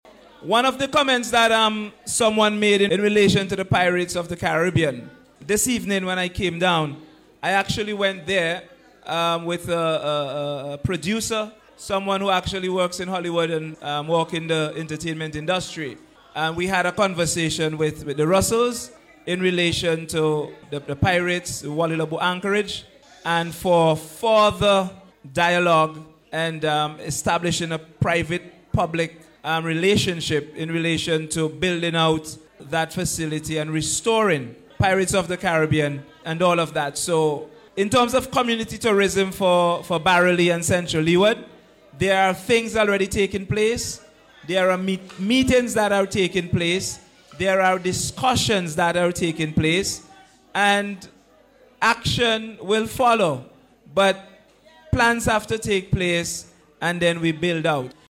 Huggins was speaking in response to concerns raised about the decay of the site, at a Community Consultation held last week.